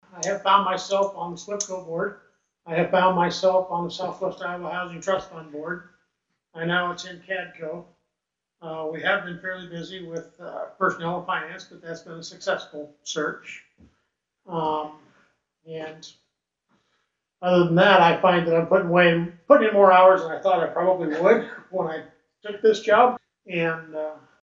(Atlantic, IA) – Atlantic Mayor Rob Clausen, Jr., Wednesday night, in his report to the Atlantic City Council, he's still adjusting to his new role as Mayor, along with various boards and committees.
He said with a chuckle, he hopes as time goes by, he can begin scaling-back on all the meetings he has to attend.